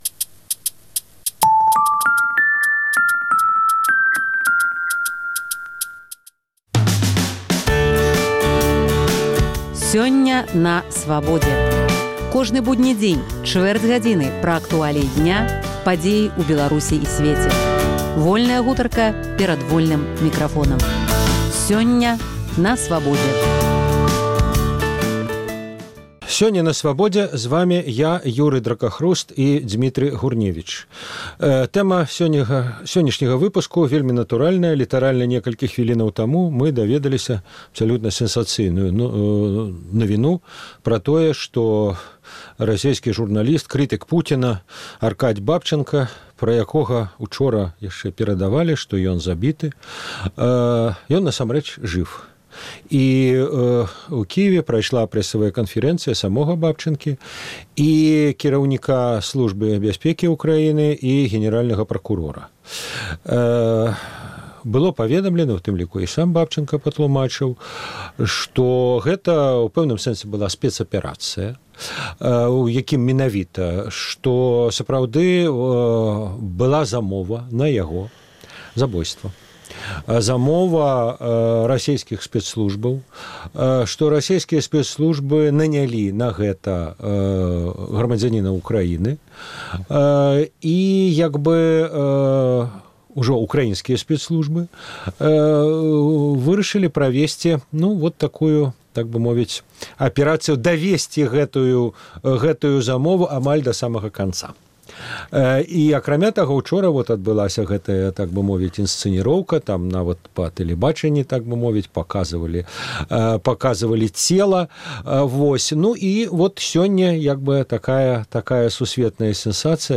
Размова